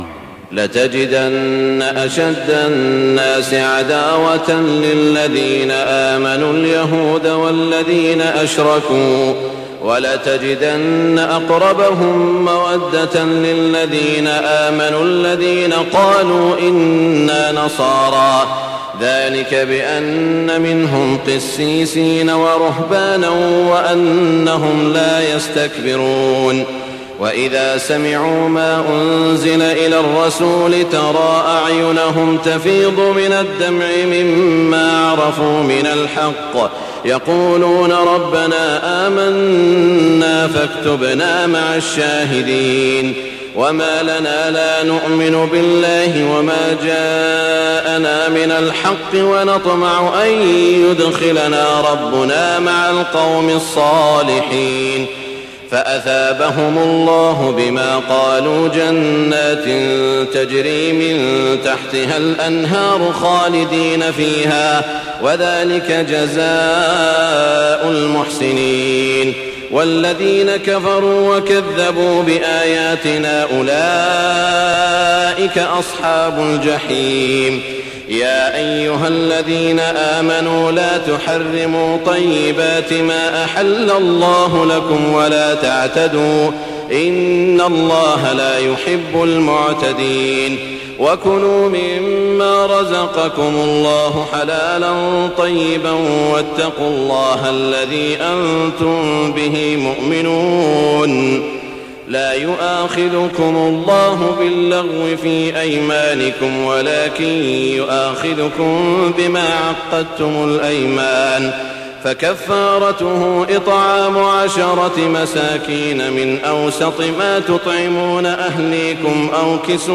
تهجد ليلة 27 رمضان 1424هـ من سورتي المائدة (82-120) و الأنعام(1-58) Tahajjud 27 st night Ramadan 1424H from Surah AlMa'idah and Al-An’aam > تراويح الحرم المكي عام 1424 🕋 > التراويح - تلاوات الحرمين